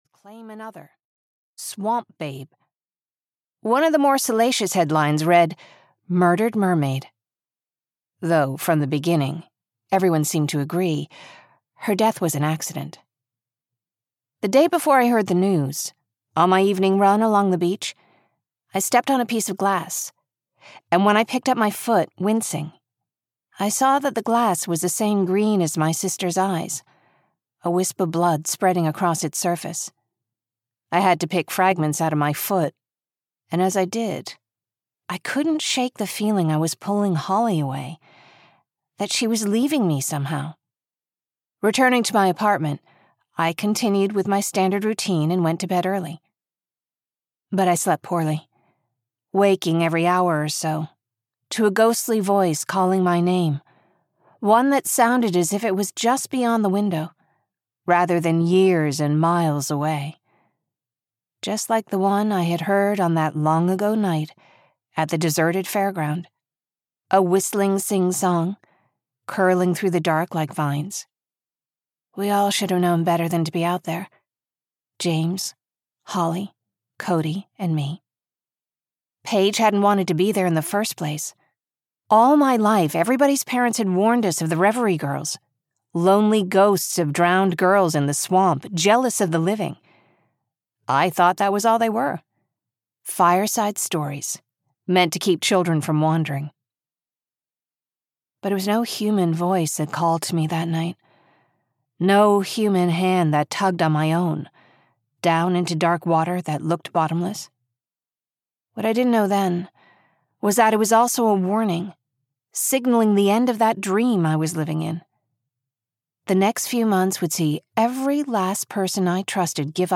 The Woman in the Water (EN) audiokniha
Ukázka z knihy